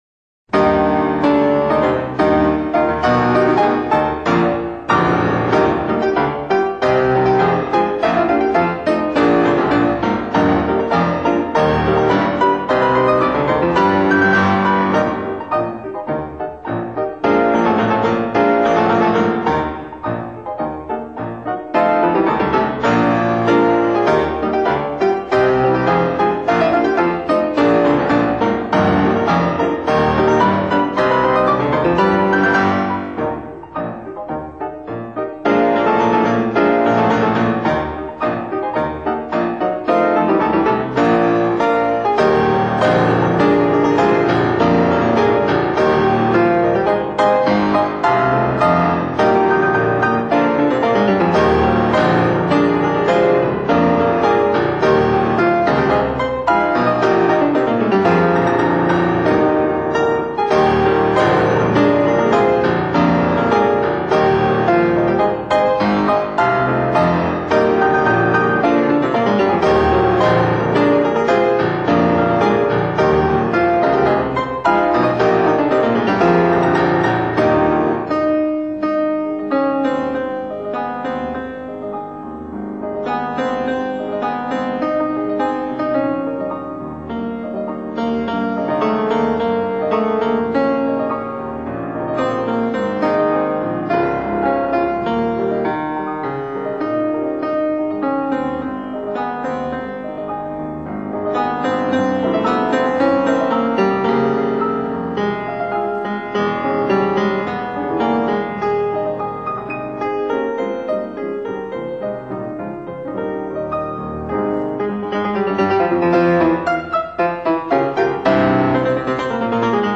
Let’s start by listening to something that’s totally not ragtime: a march by the “March King,” John Philip Sousa (1854-1932). This is his work The Stars and Stripes Forever (1897), performed by the pianist Vladimir Horowitz (1903-89).
It does what marches are supposed to do: march along steadily and squarely in a duple meter (one-two, one-two, one-two, just like marching feet).
sousa-the-stars-and-stripes-forever-vladimir-horowitz.mp3